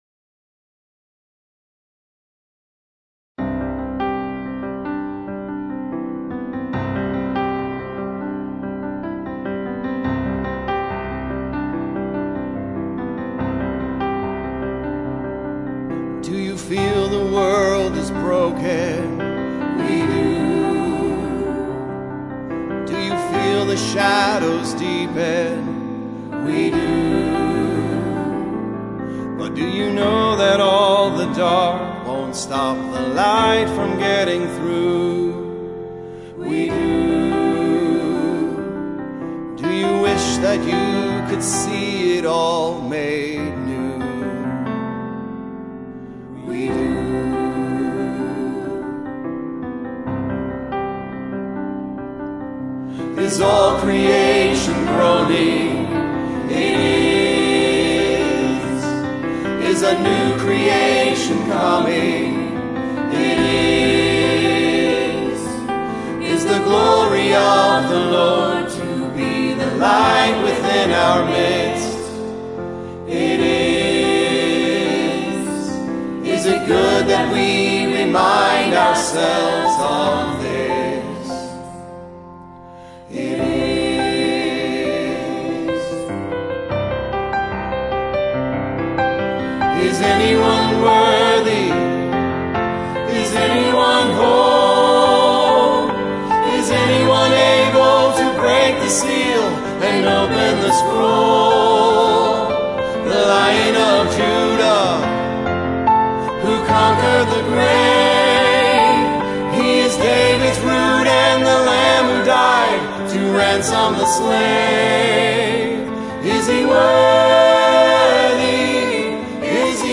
Worship-Songs-March-29.mp3